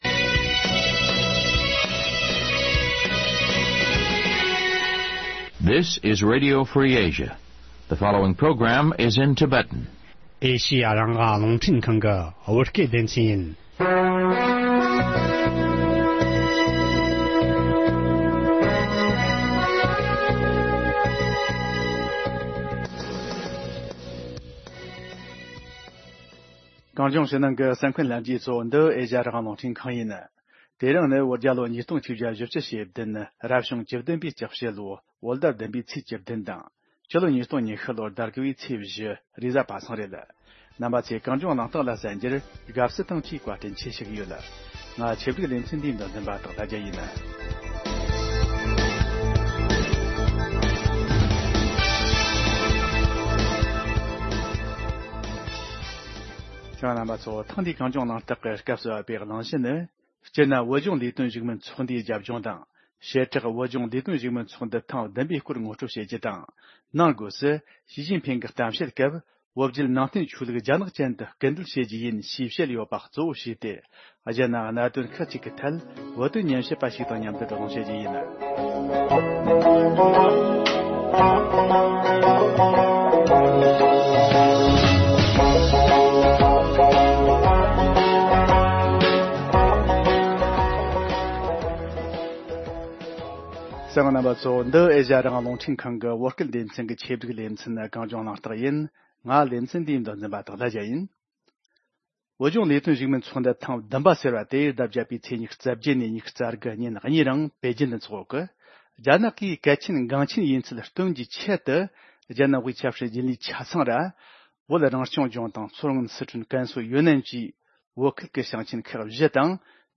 བགྲོ་གླེང་ཞུས་པར་གསན་རོགས།